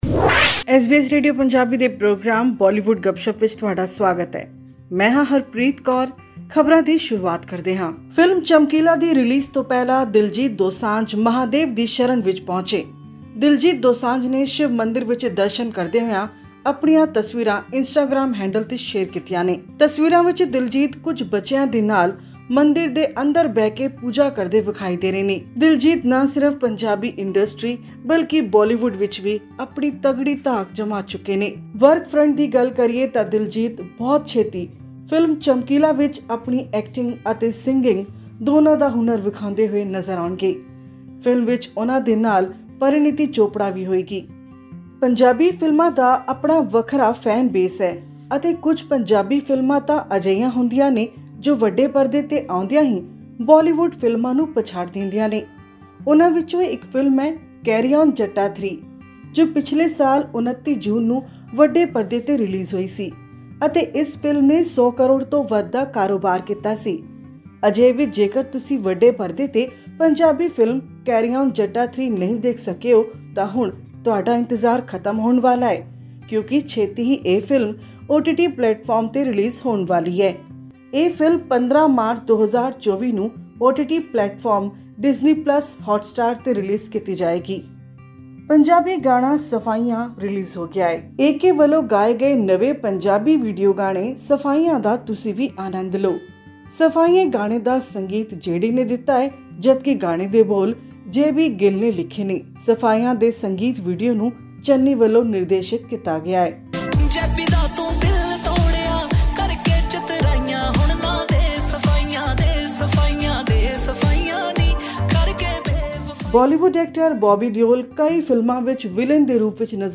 100 ਕਰੋੜ ਤੋਂ ਜਿਆਦਾ ਦੀ ਕਮਾਈ ਕਰਨ ਵਾਲੀ ਫਿਲਮ ਕੈਰੀ ਓਨ ਜੱਟਾ-3 ਹੁਣ ਓਟੀਟੀ ਪਲੇਟਫਾਰਮ ਤੇ ਜਾਰੀ ਹੋ ਰਹੀ ਹੈ। ਅਜਿਹੀਆਂ ਹੋਰ ਬਹੁਤ ਸਾਰੀਆਂ ਫਿਲਮੀ ਖਬਰਾਂ ਅਤੇ ਨਵੇਂ ਜਾਰੀ ਹੋਣ ਵਾਲੇ ਗੀਤਾਂ ਦੇ ਮੁਖੜੇ ਸੁਣਨ ਲਈ ਜੁੜੋ ਸਾਡੀ ਹਫਤਾਵਾਰੀ ਬਾਲੀਵੁੱਡ ਗੱਪਸ਼ੱਪ ਨਾਲ।